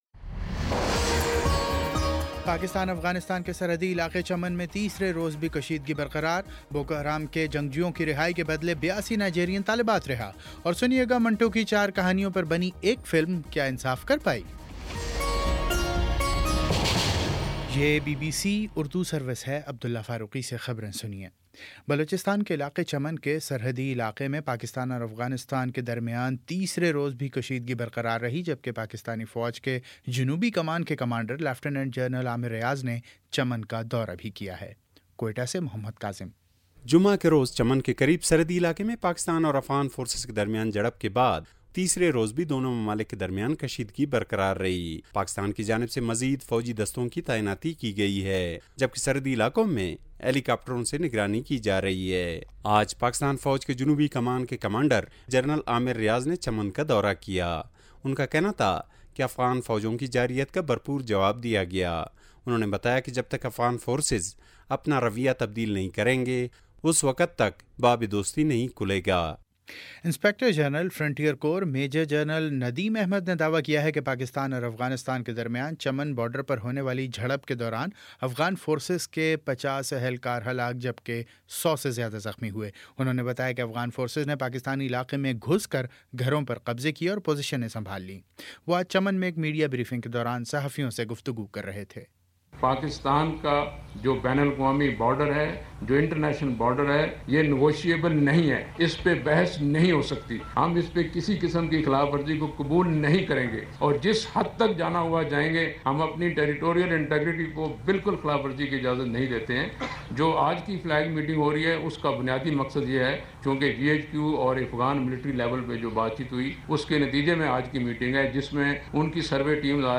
مئی 07 : شام چھ بجے کا نیوز بُلیٹن